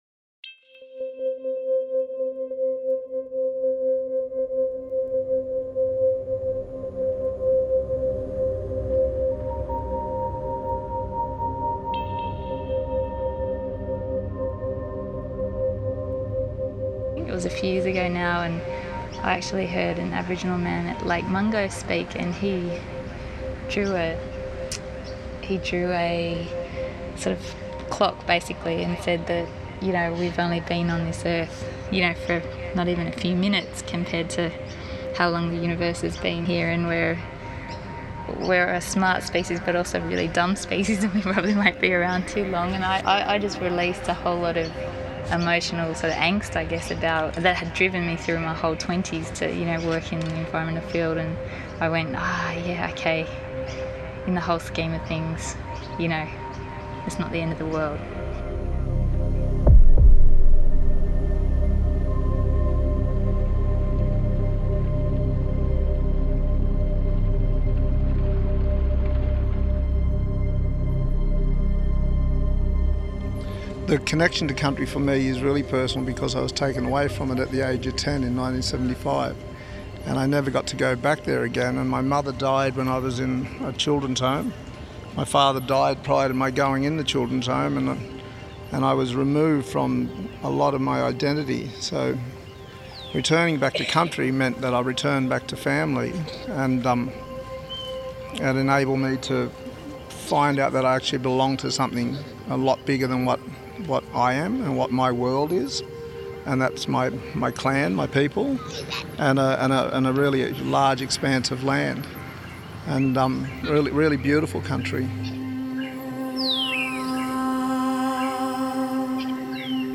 Sound voices
01-Solastalgia-Soundtrack.mp3